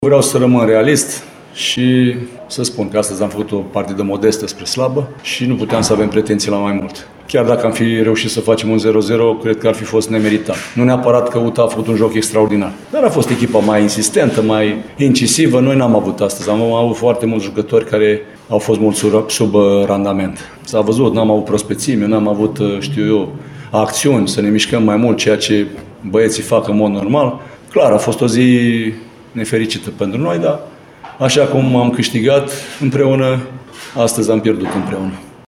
De partea cealaltă, Leo Grozavu (FC Botoșani) a admis că echipa lui a avut o prestație ”modestă spre slabă” în jocul de pe arena ”Francisc Neuman”: